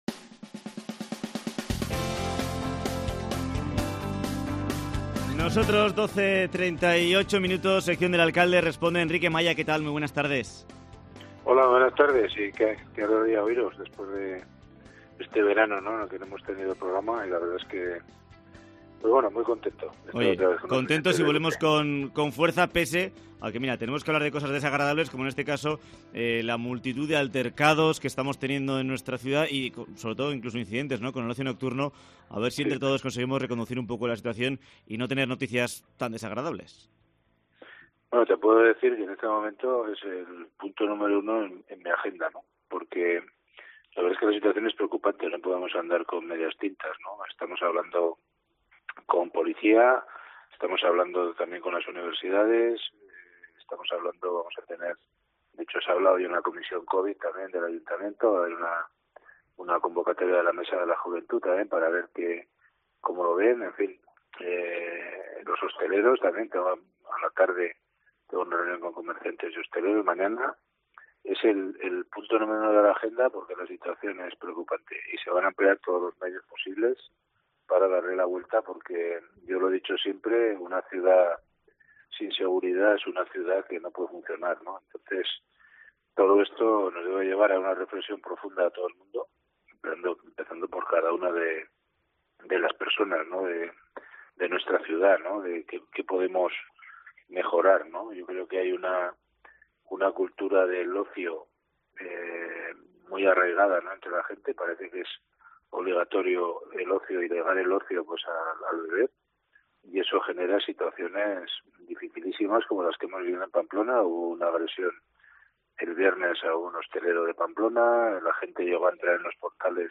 Entrevista con Enrique Maya, alcalde Pamplona